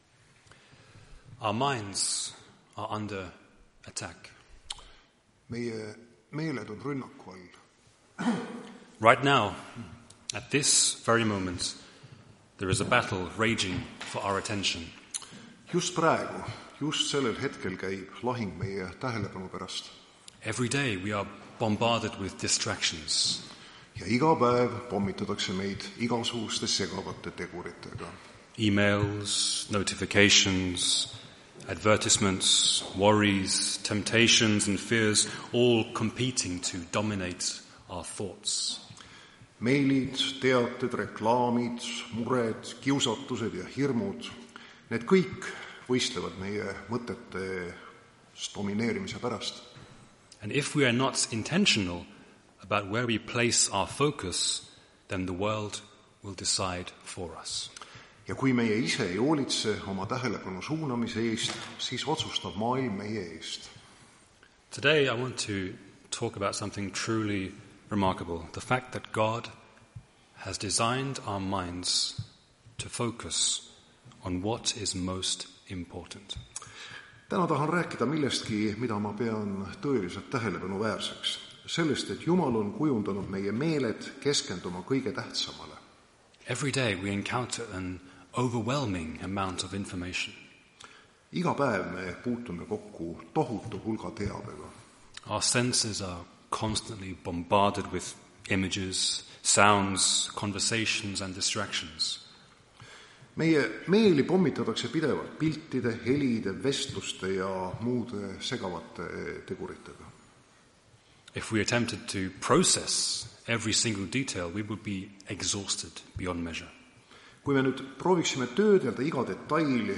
Tartu adventkoguduse 15.02.2025 hommikuse teenistuse jutluse helisalvestis.